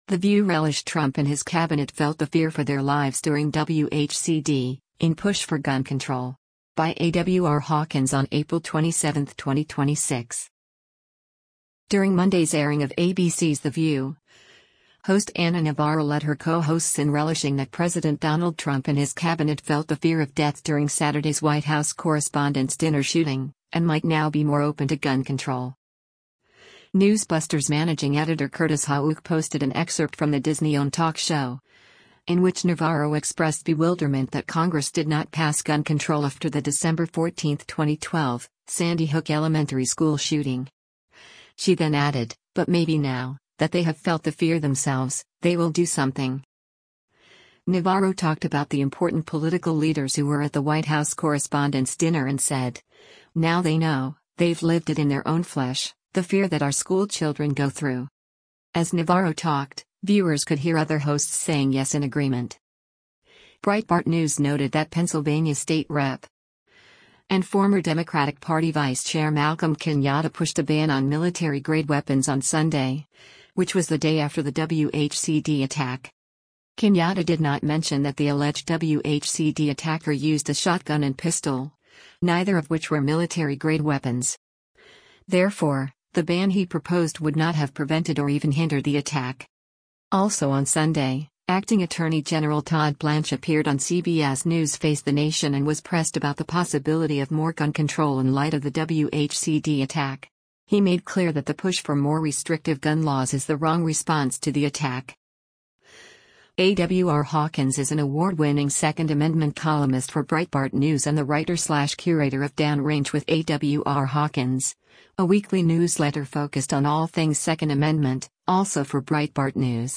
During Monday’s airing of ABC’s The View, host Ana Navarro led her co-hosts in relishing that President Donald Trump and his Cabinet “felt the fear” of death during Saturday’s White House Correspondents’ Dinner shooting, and might now be more open to gun control.
As Navarro talked, viewers could hear other hosts saying “yes” in agreement.